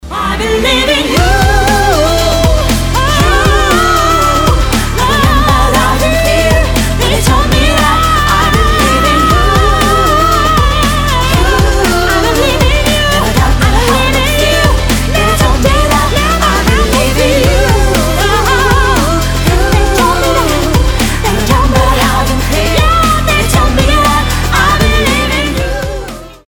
• Качество: 320, Stereo
поп
громкие
женский вокал